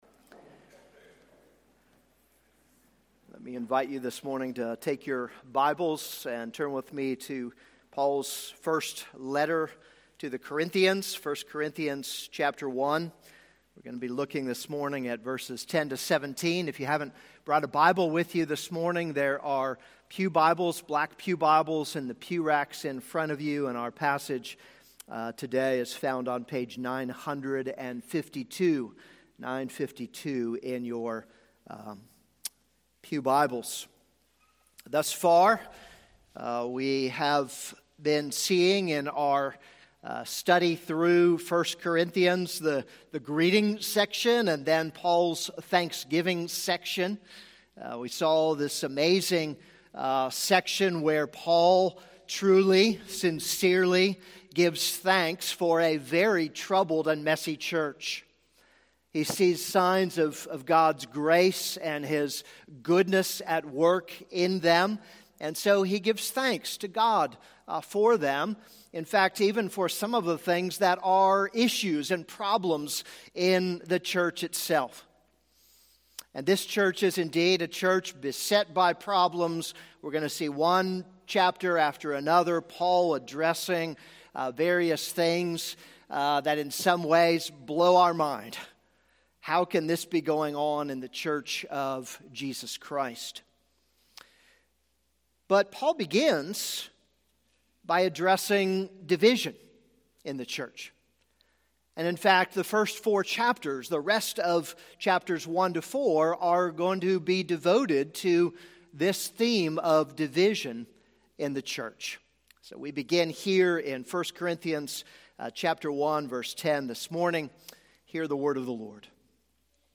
This is a sermon on 1 Corinthians 1:10-17.